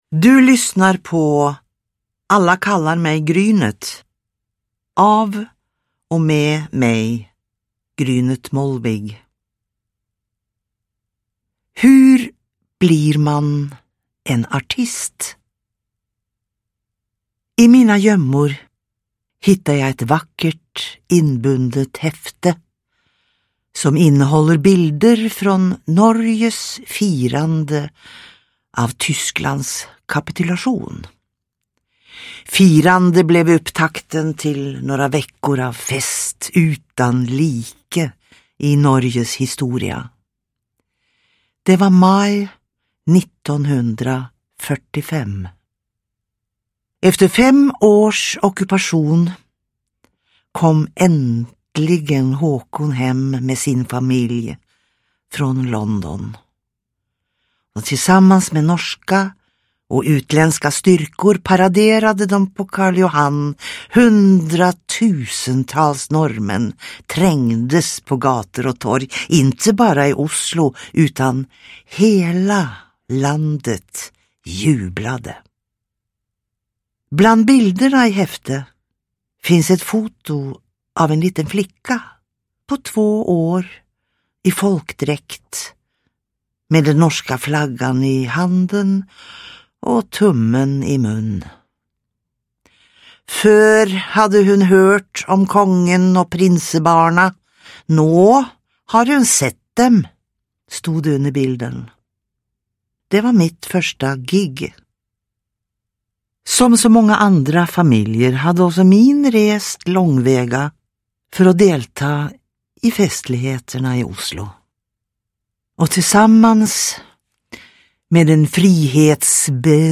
Alla kallar mig Grynet – Ljudbok
Uppläsare: Grynet Molvig